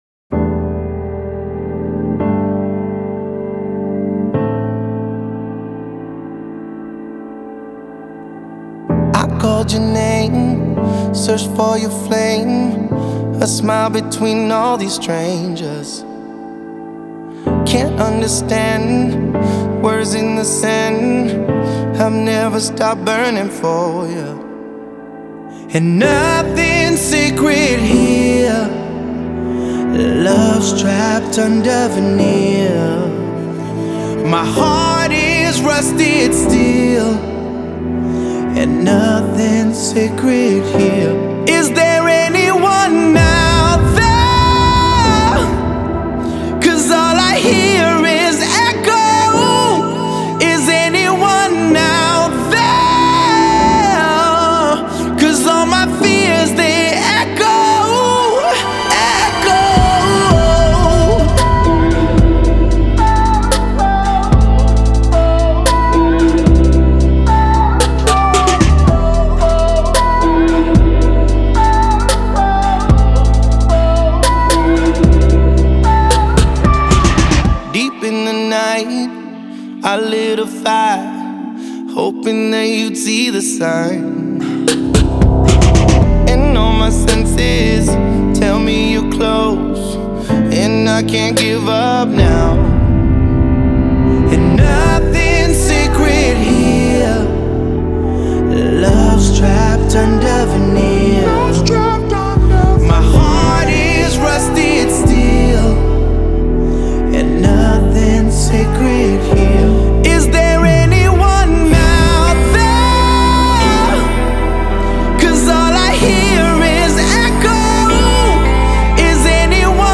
это энергичная электронная композиция в стиле EDM